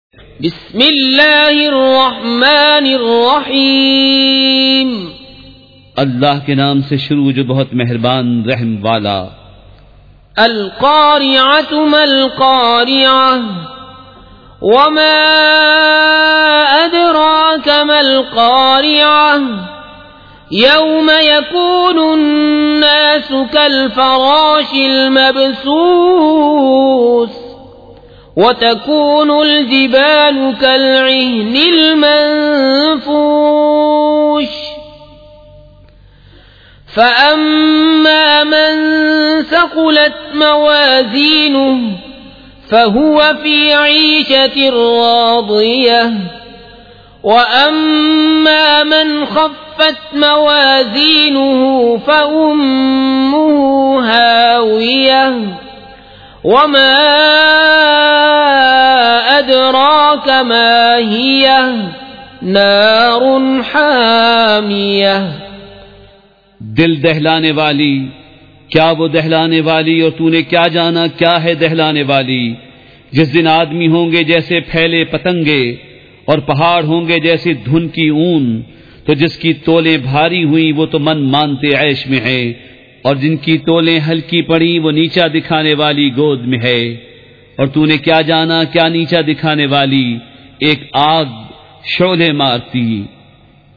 سورۃ القارعۃ مع ترجمہ کنزالایمان ZiaeTaiba Audio میڈیا کی معلومات نام سورۃ القارعۃ مع ترجمہ کنزالایمان موضوع تلاوت آواز دیگر زبان عربی کل نتائج 2565 قسم آڈیو ڈاؤن لوڈ MP 3 ڈاؤن لوڈ MP 4 متعلقہ تجویزوآراء